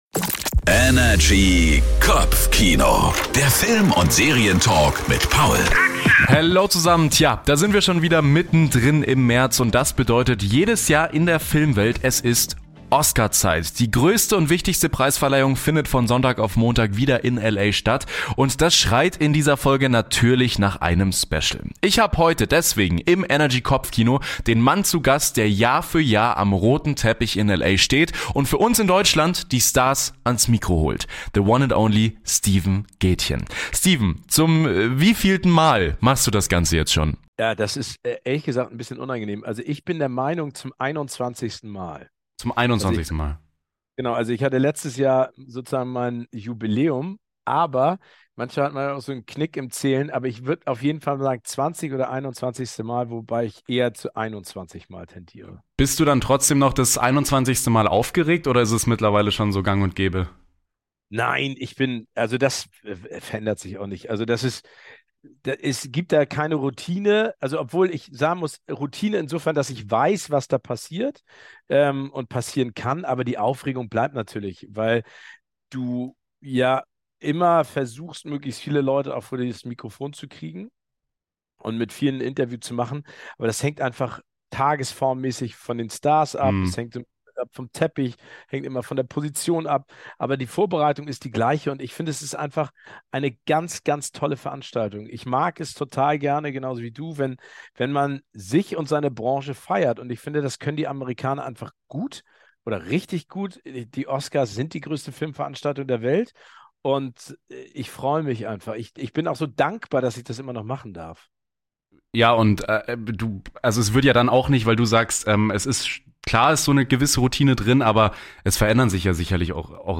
Kurz vor der Verleihung der Oscars haben wir Steven Gätjen zu Gast. Wie bereitet er sich auf den Teppich vor und was hat er mit Dwayne "The Rock" Johnson gemeinsam und welche Filme und Stars werden wohl gewinnen?